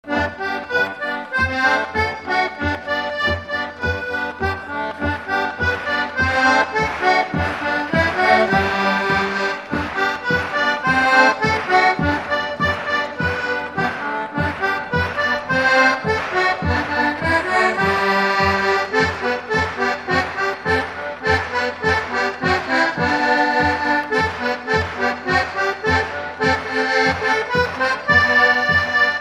Instrumental
Pièce musicale inédite